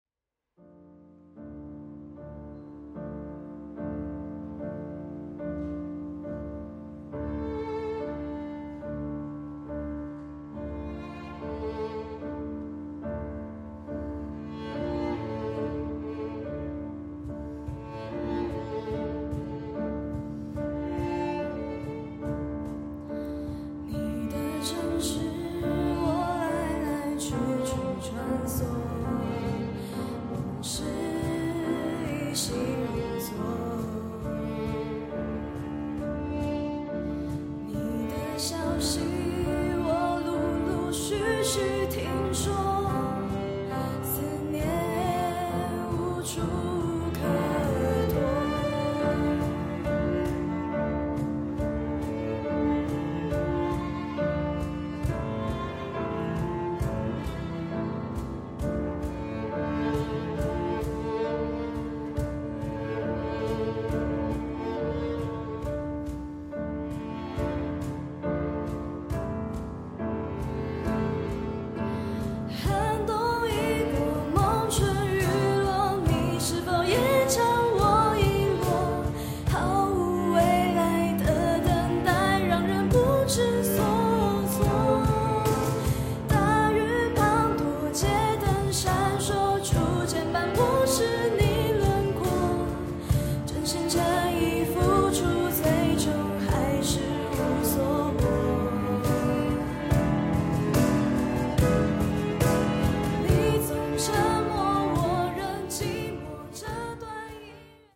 Solo Music, Soul jazz, Contemporary Christian